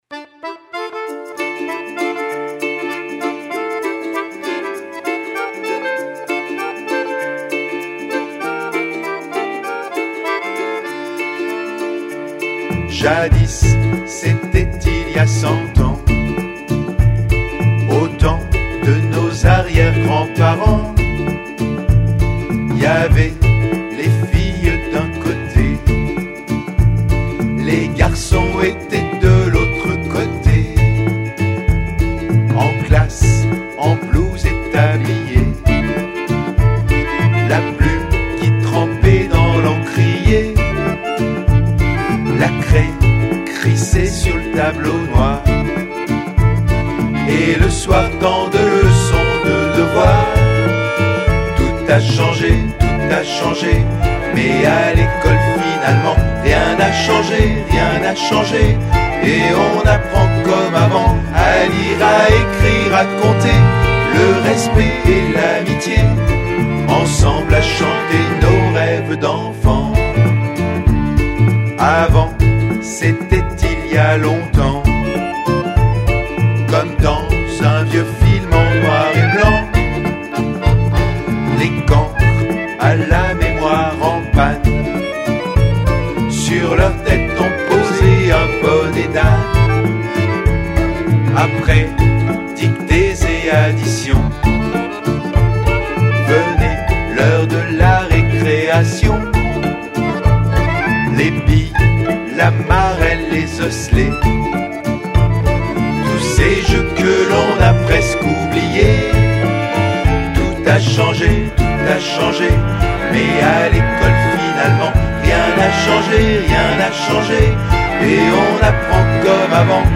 « Viva la fiesta francophone » et « Tout a changé rien a changé » ont retenti dans les couloirs de l’école et ont été chanté par toutes les classes de l’élémentaire lors de la fête du centenaire le 16 novembre !